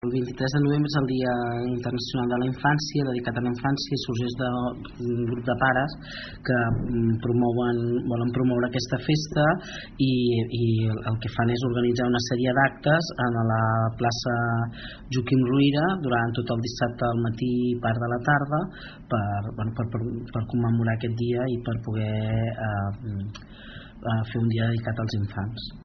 En parla Susanna Pla, regidora d’Educació de l’Ajuntament de Palafolls.